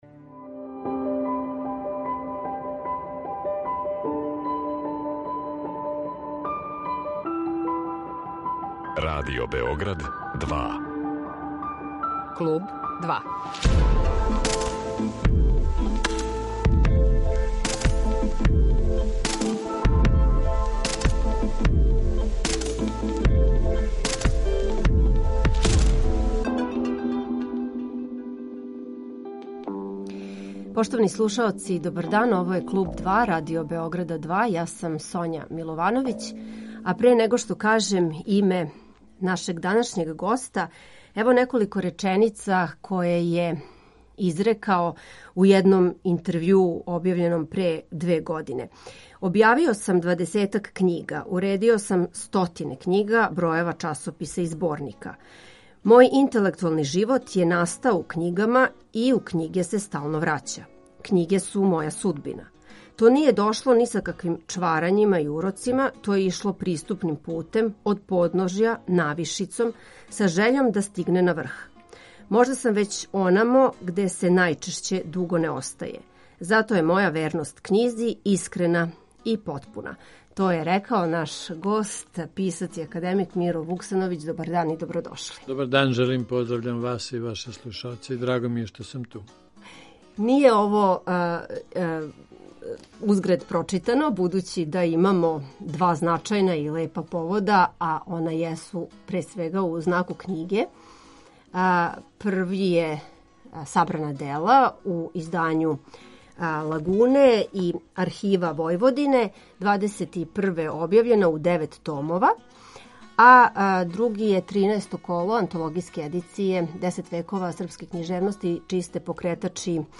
Гост Клуба 2 је књижевник и академик Миро Вуксановић